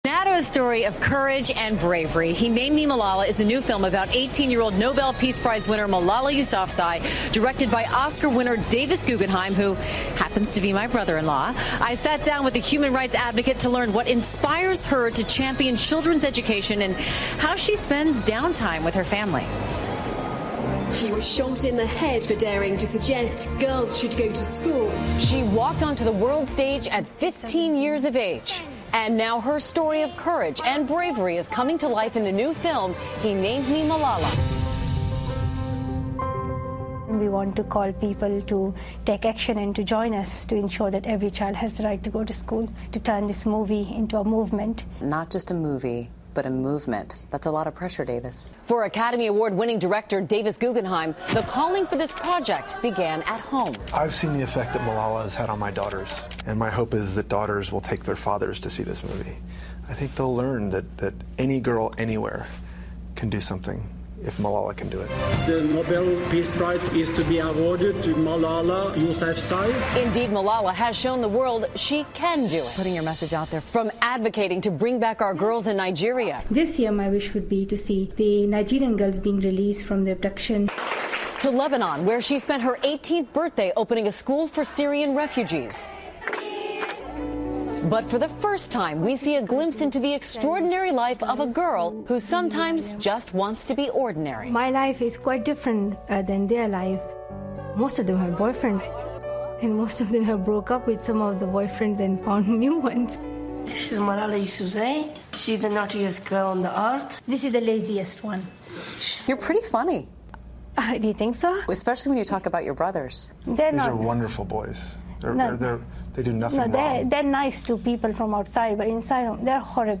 访谈录 诺贝尔和平奖得主马拉拉专访 听力文件下载—在线英语听力室